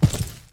FootstepHeavy_Concrete 08.wav